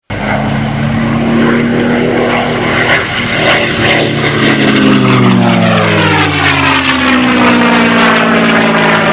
P-51.mp3